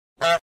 Goose Honk Sound Effect Free Download
Goose Honk